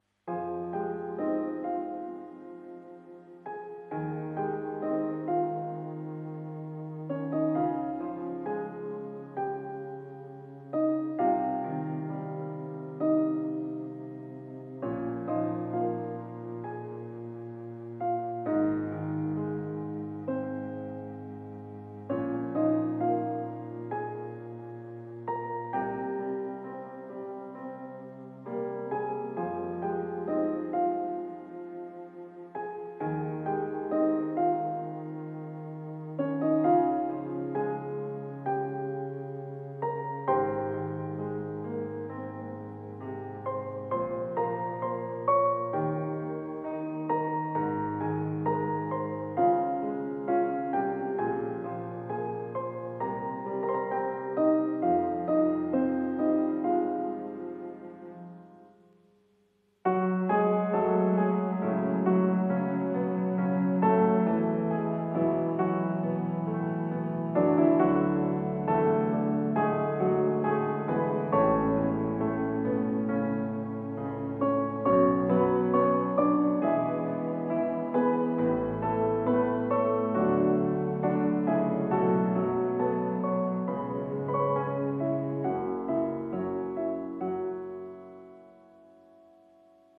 一人声劇】漆黒を照らすは花明かり